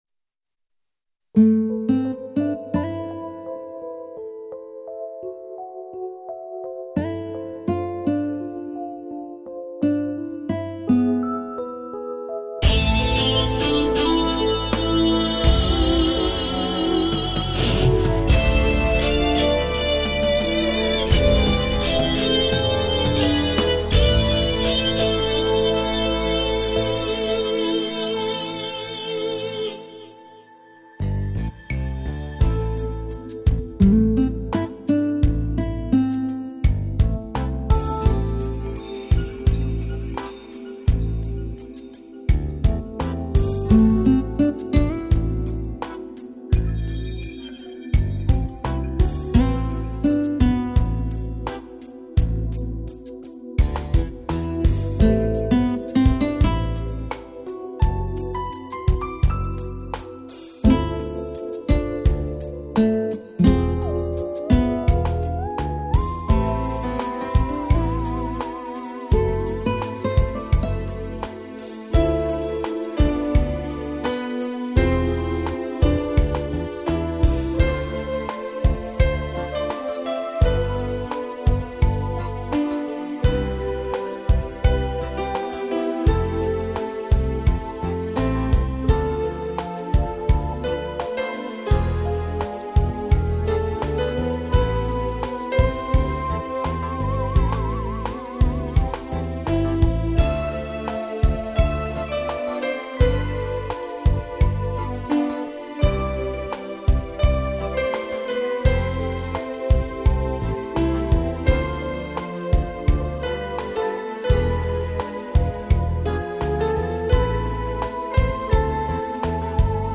Понятно, что с профессиональной студийной записью рядом не поставишь, но всё равно душу греет...